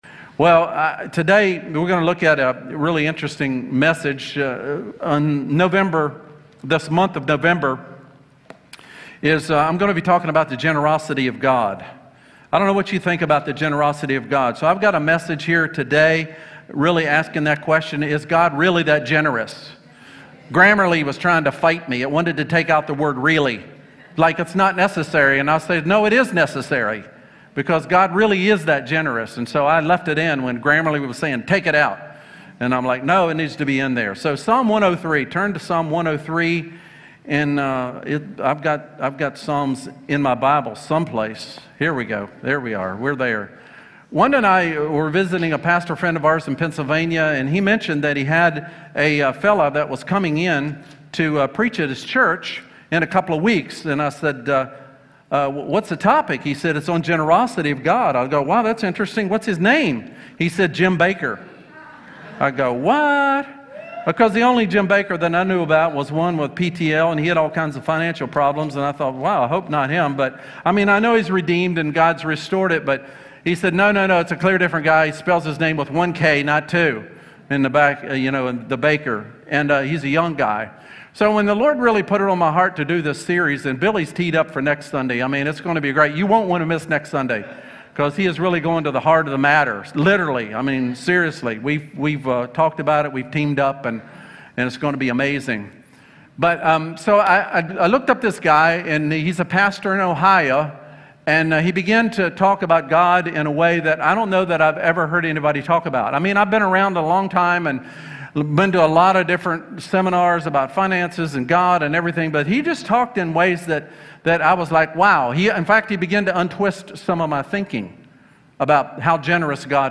Sermon | Crossroads Community Church